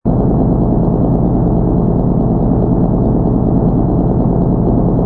engine_ci_freighter_loop.wav